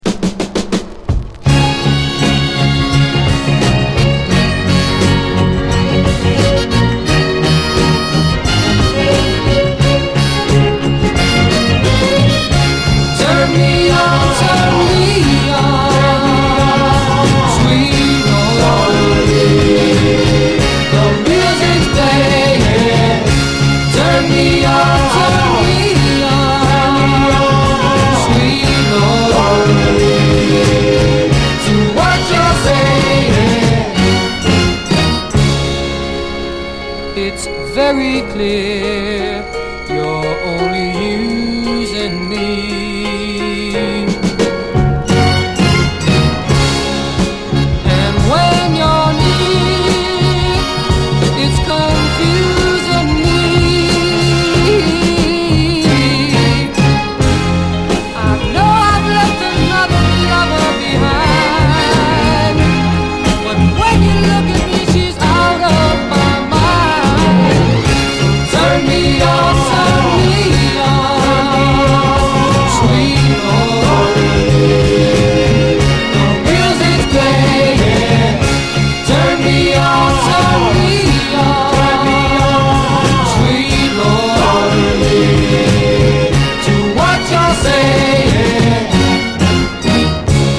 AORファンを魅了してやまないコーラス・ハーモニーとメロディーがとにかくハイ・クオリティー！
ディスコ調なブルーアイド・ソウル